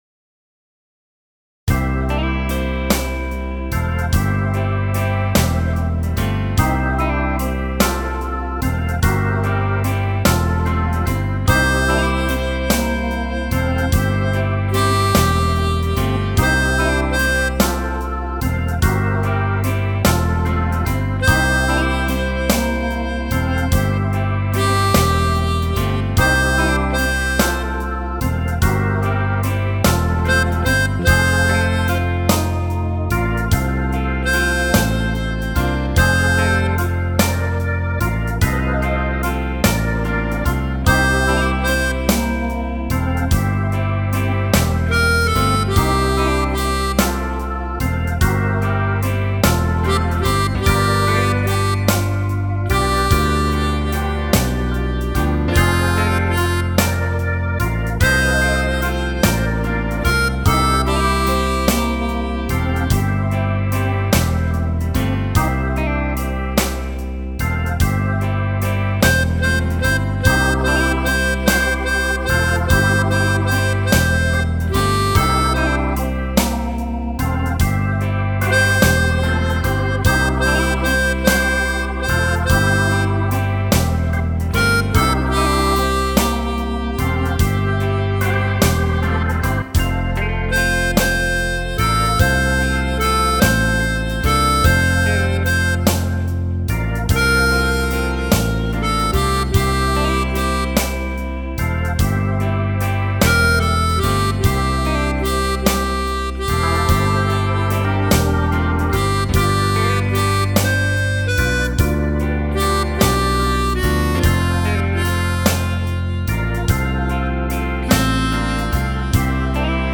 Der Blues in mir ist ein Stück das ich am 24. Dezember 2025 improvisiert habe.
BluesAudio_017.mp3